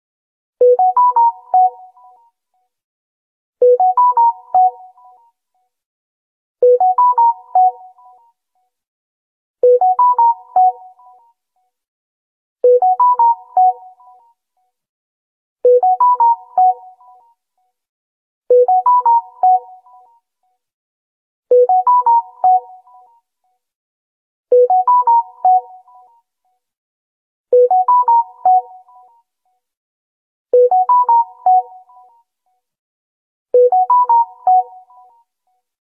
Explore the complete collection of Samsung Galaxy Note 9 notification sounds in this comprehensive video. From classic alerts to unique tones, experience each sound to customize your device to your liking.